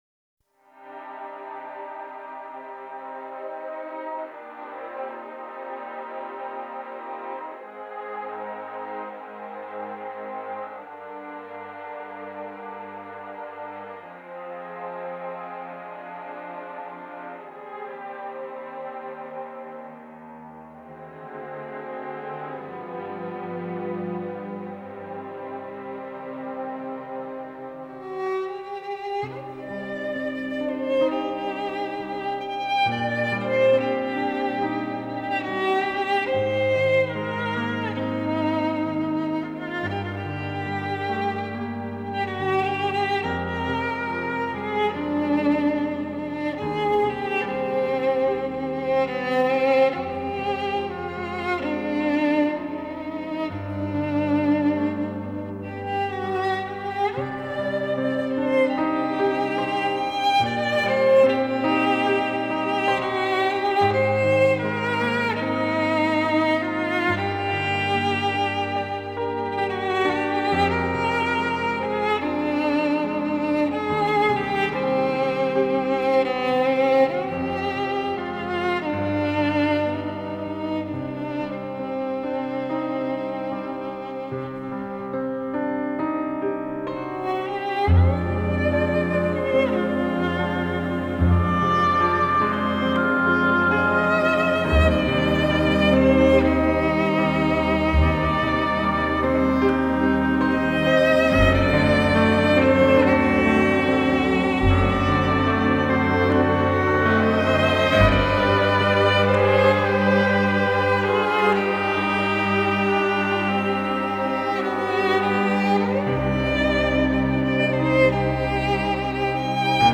موزیک بی کلام ویولن